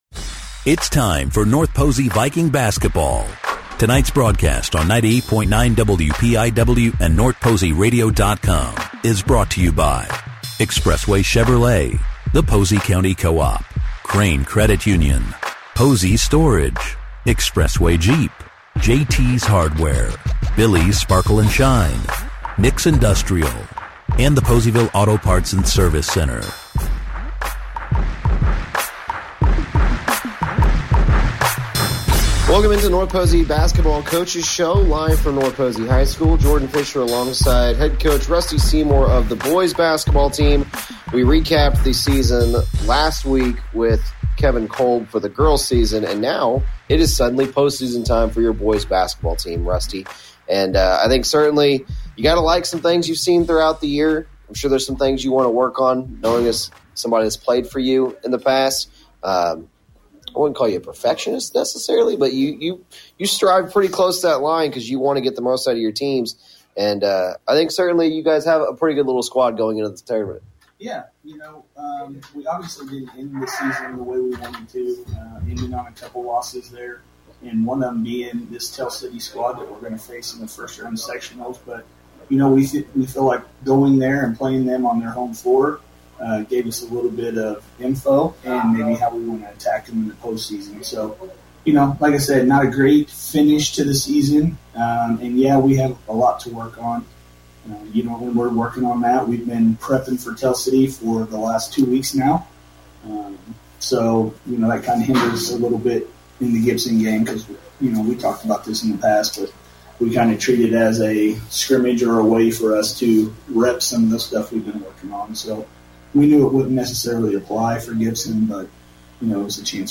North Posey Basketball Coaches Show 03-03-25